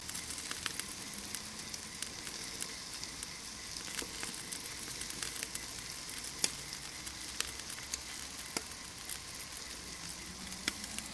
Звуки гриля
Шум мяса на гриле